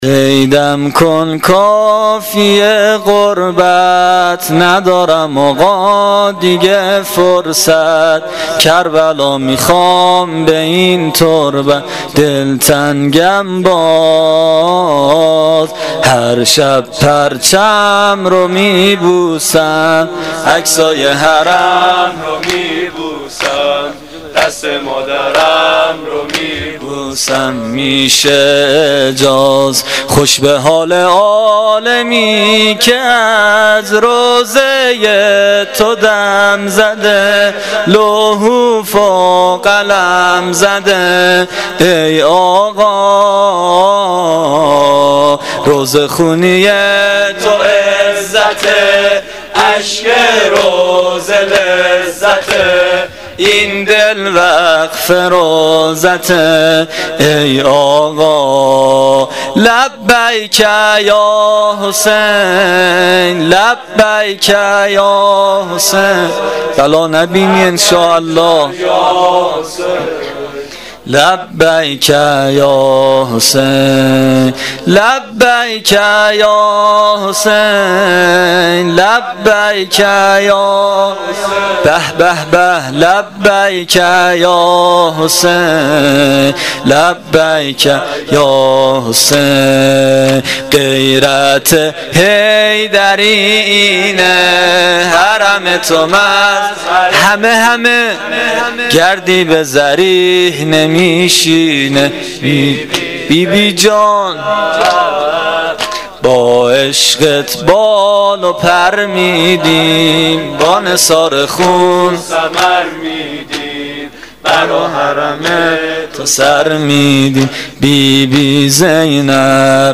• Shabe06 Moharram1396[10]-Sorood Payani.mp3